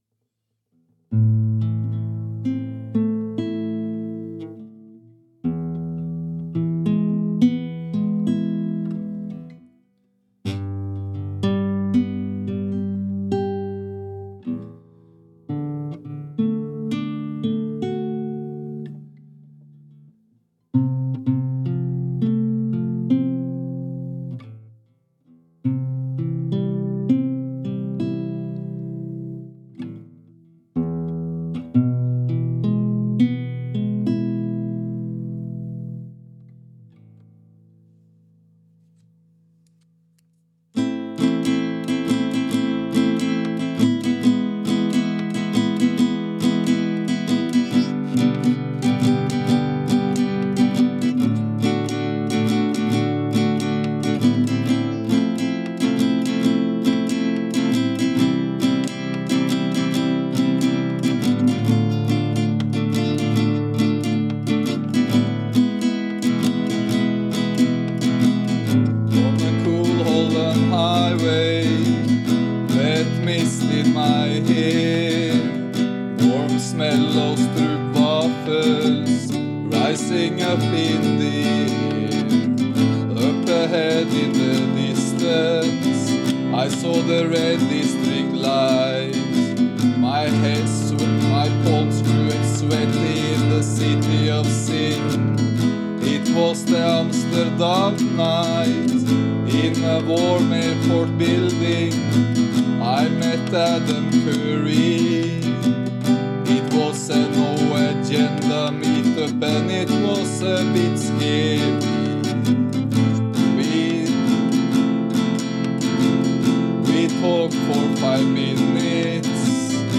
A Value4Value Music Podcast.